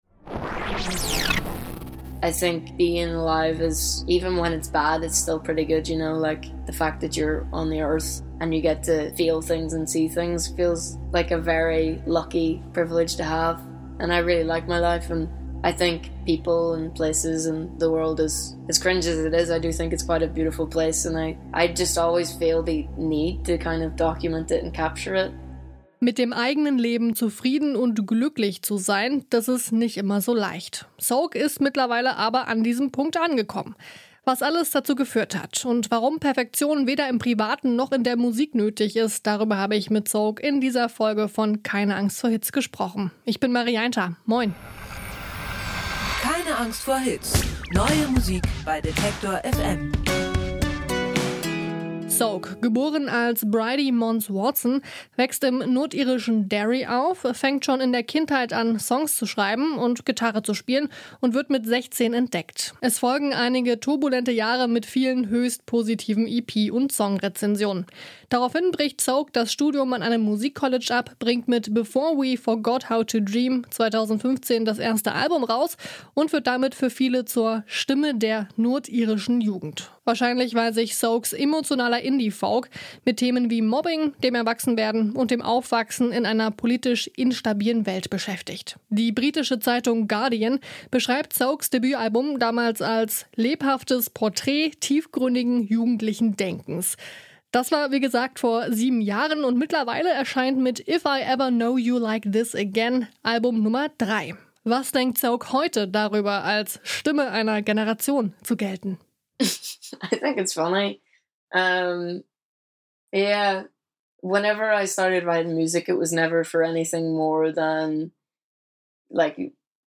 Interview: SOAK